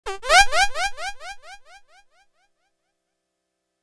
Rubber Ducky 2.wav